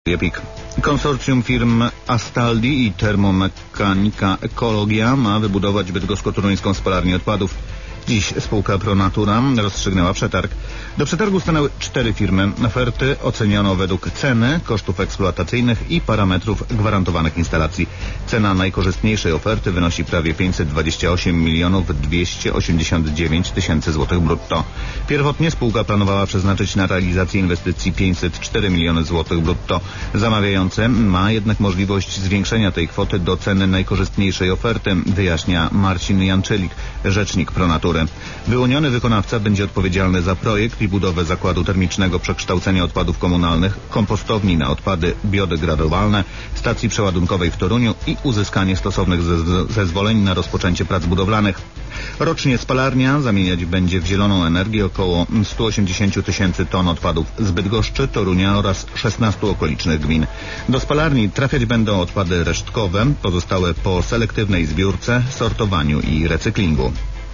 Pobierz plik (pr_pik_bydgoszcz-2011_12_23_15_01_55-wiadomosci.wav)pr_pik_bydgoszcz-2011_12_23_15_01_55-wiadomosci.wav[ ]0 kB